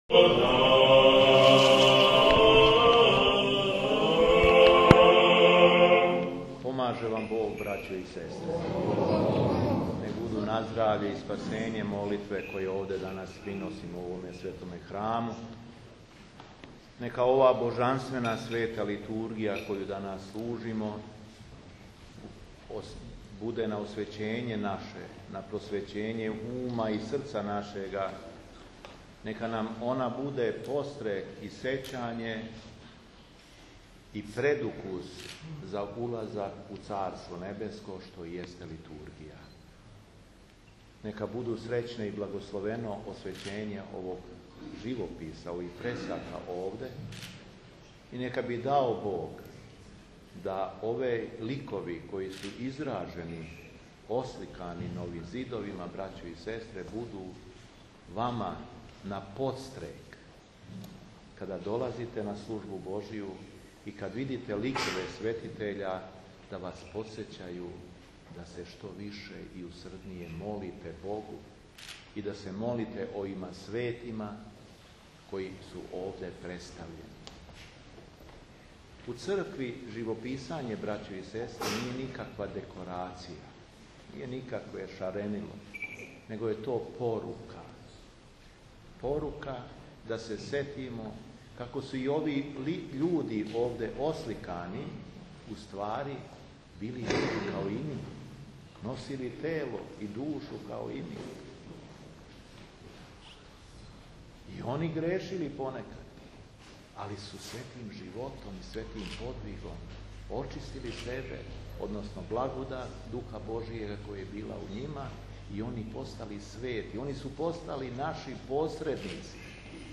ЛИТУРГИЈА У ХРАМУ СВЕТОГ ПРОРОКА ИЛИЈЕ У БРЗАНУ
Беседа